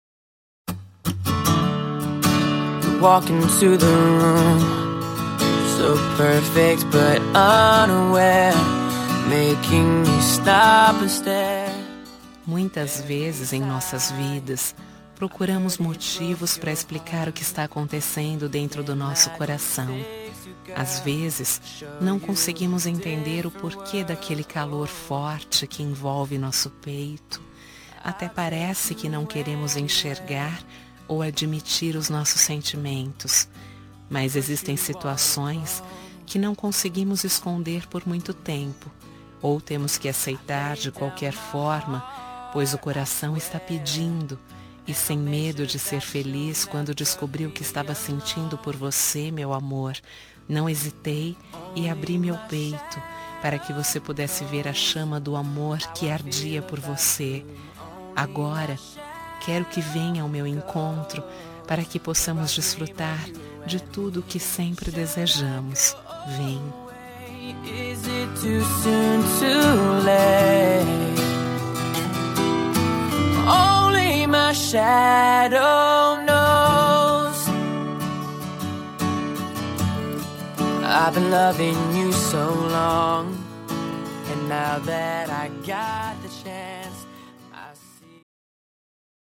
Telemensagem Início de Namoro – Voz Feminina – Cód: 742